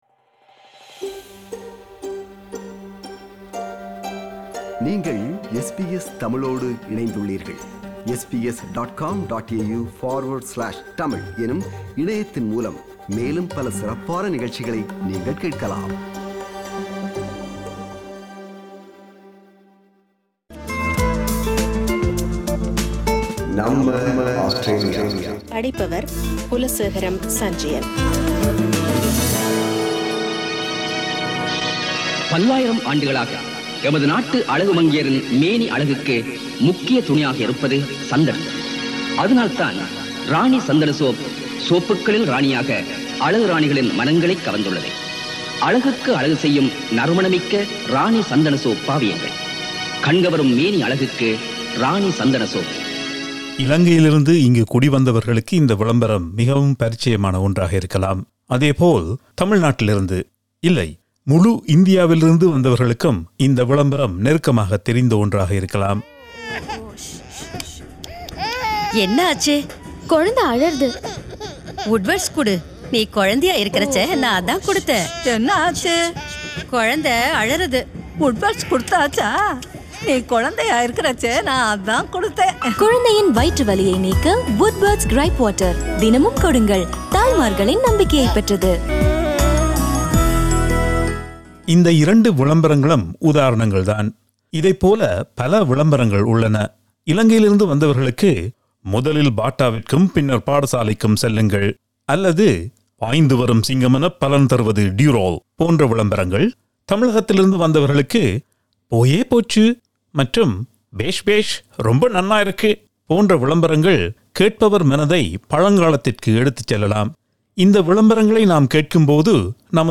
Louie the Fly and Aeroplane Jelly Source: NATIONAL FILM AND SOUND ARCHIVE OF AUSTRALIA